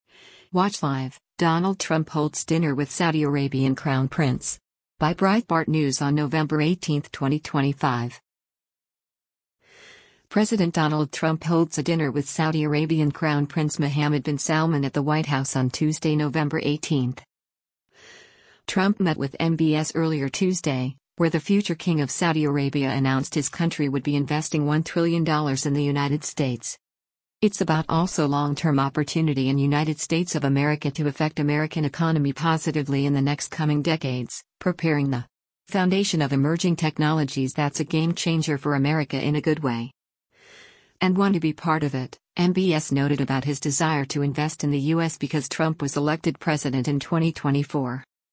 President Donald Trump holds a dinner with Saudi Arabian Crown Prince Mohammed bin Salman at the White House on Tuesday, November 18.